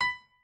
pianoadrib1_59.ogg